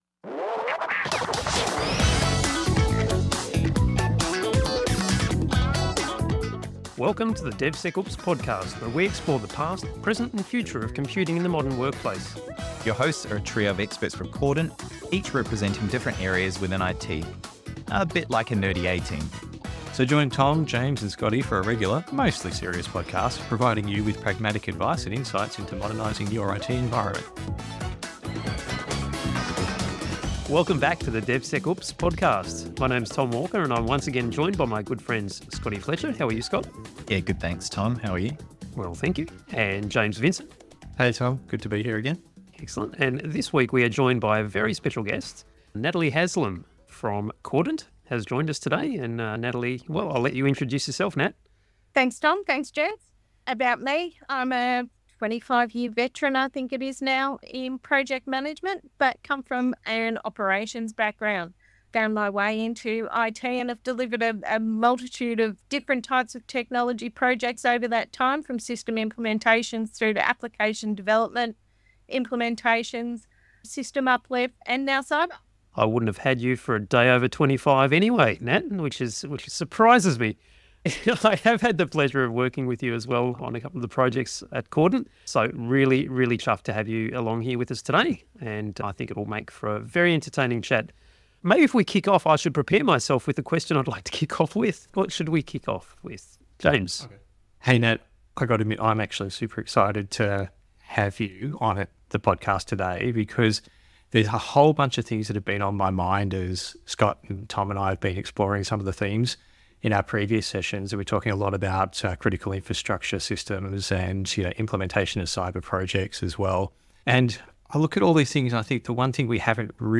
The discussion covers the importance of involving operational teams early, managing cybersecurity incidents during project delivery, and balancing governance with agility. The team also examines project management methodologies, debating agile versus waterfall approaches and the benefits of a hybrid mode, and the value of stakeholder engagement, advocating for clear communication to secure buy-in and drive successful cyber initiatives.